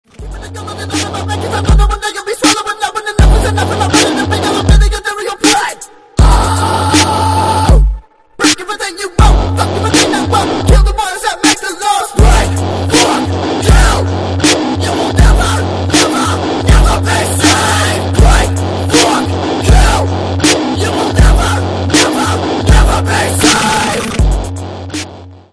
громкие
жесткие
пугающие
Bass
Alternative Rap
устрашающие